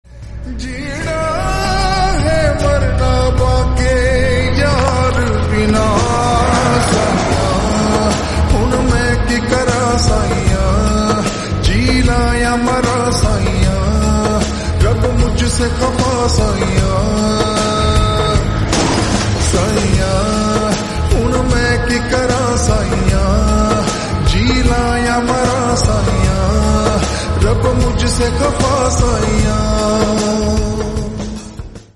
Hindi Ringtones